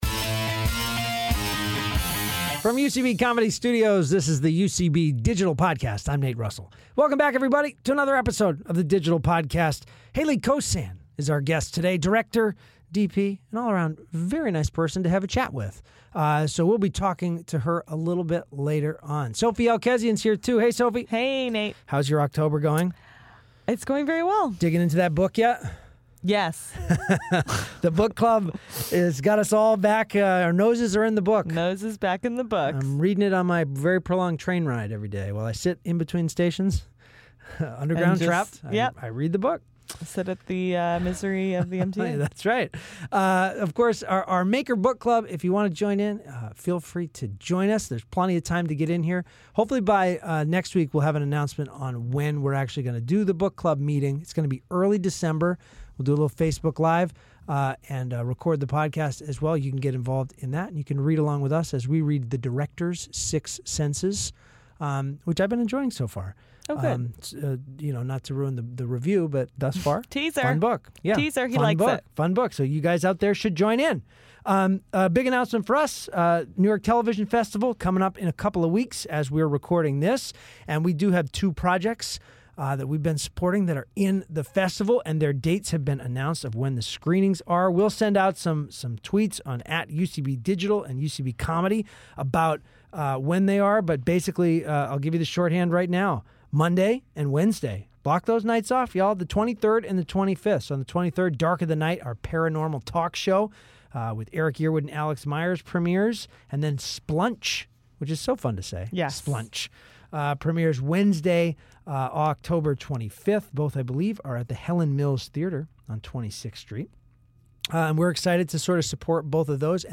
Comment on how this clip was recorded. in the studio this week